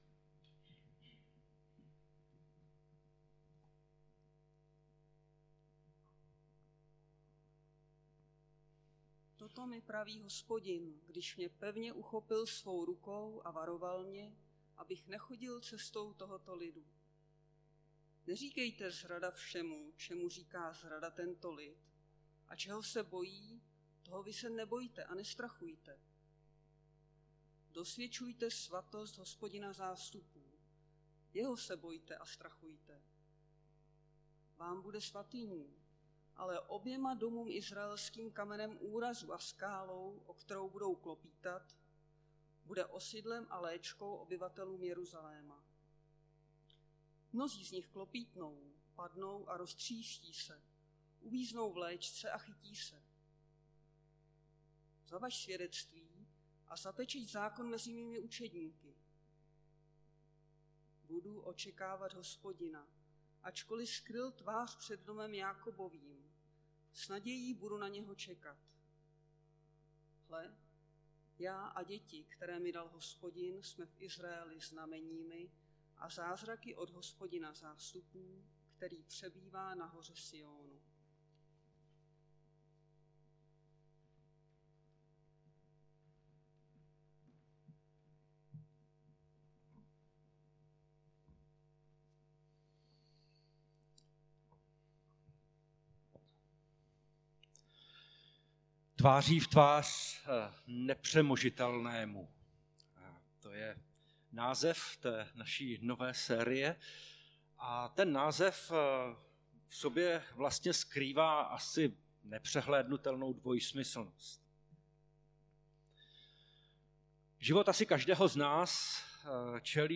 Nedělní kázání – 5.3.2023 Tvář v tvář strachu